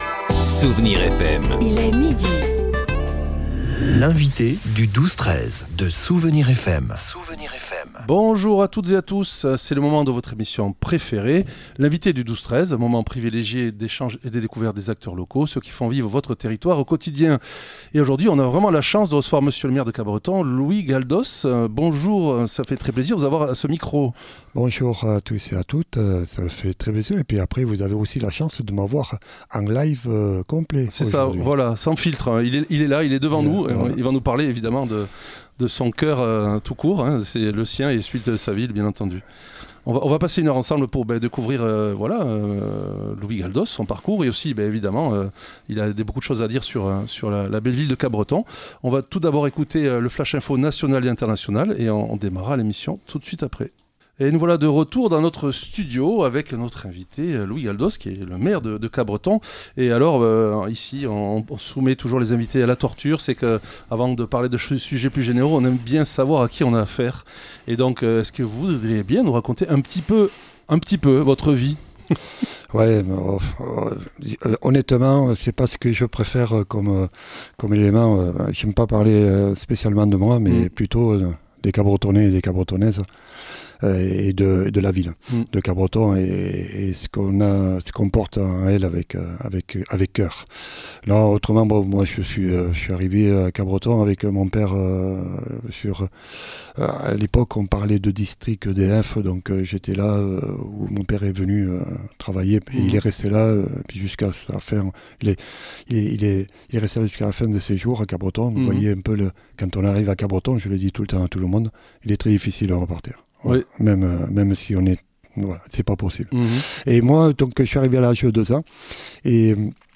L'invité(e) du 12-13 de Soustons recevait aujourd'hui Louis Galdos, maire de Capbreton.